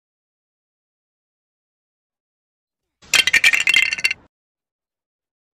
Day 1#roblox#edits#capcut#legofallingbricksound sound effects free download